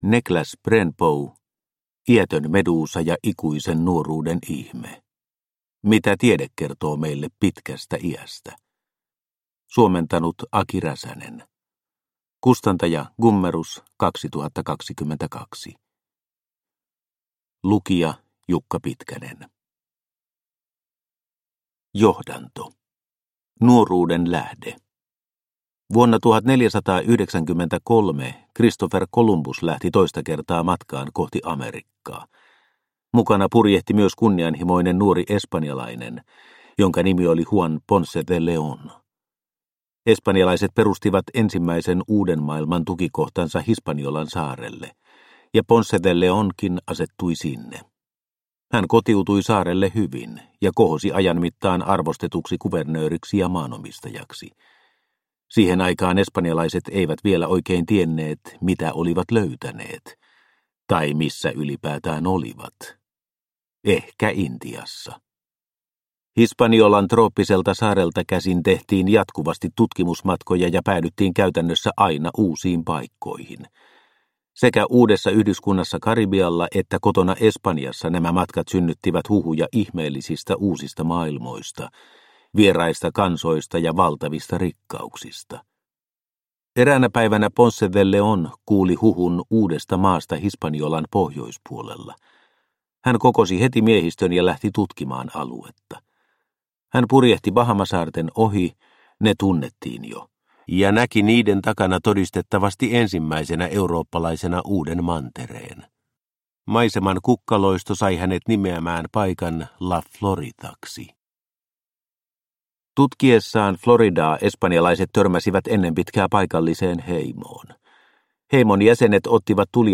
Iätön meduusa ja ikuisen nuoruuden ihme – Ljudbok – Laddas ner